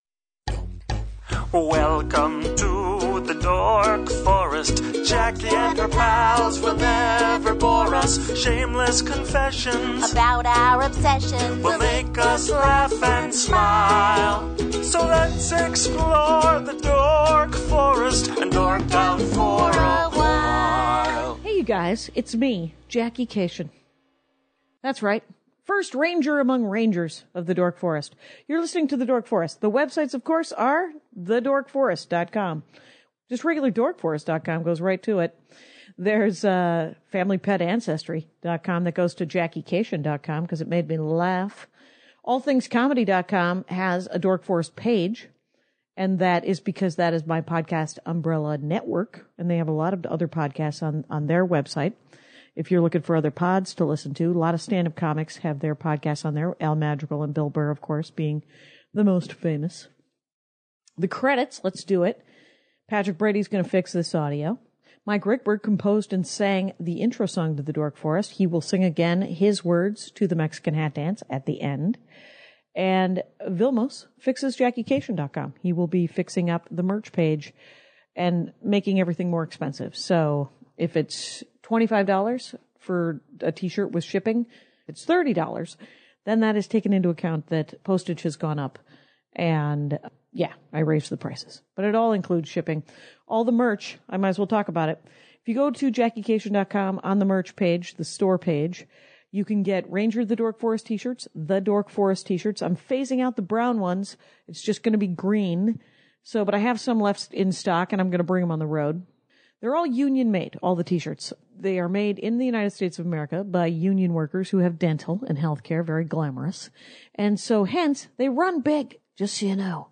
I’m a sloppy talker in this one but he’s great!